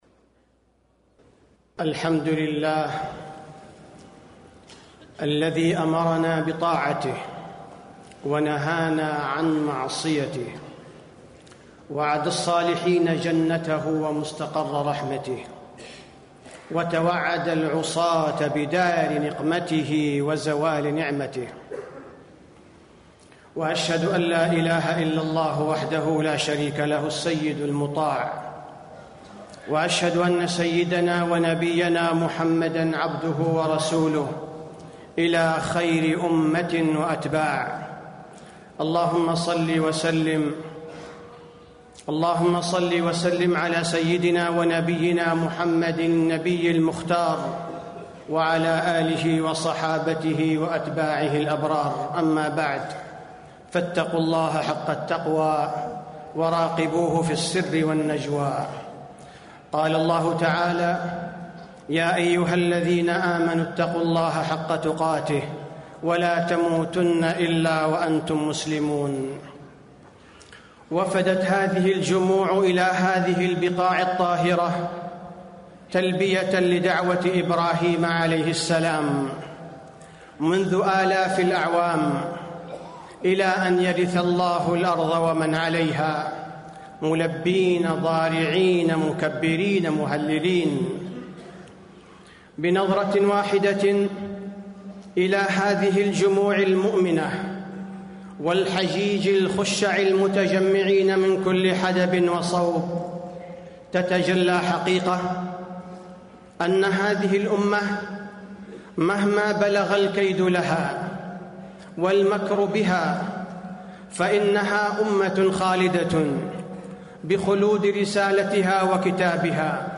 تاريخ النشر ٣ ذو الحجة ١٤٣٣ هـ المكان: المسجد النبوي الشيخ: فضيلة الشيخ عبدالباري الثبيتي فضيلة الشيخ عبدالباري الثبيتي فضل الحج والعشر من ذي الحجة